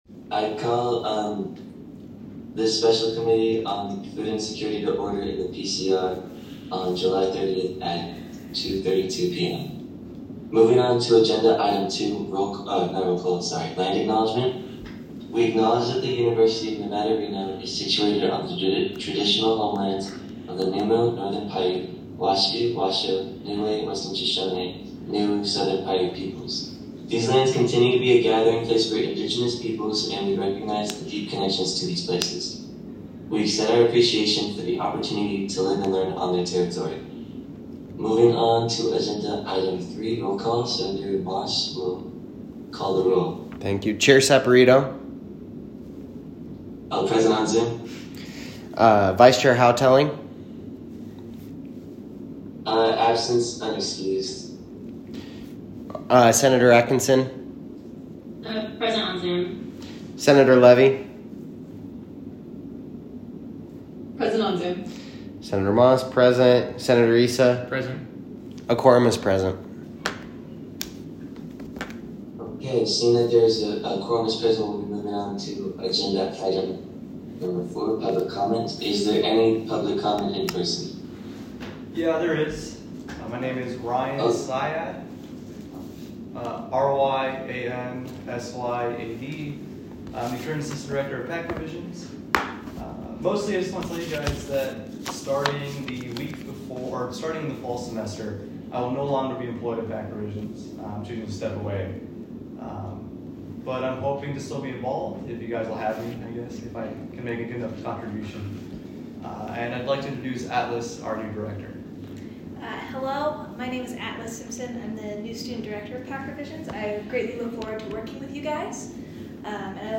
Special Committee on Food Insecurity Meeting
Location : Joe Crowley Student Union – Frankie Sue Del Papa President’s
Audio Minutes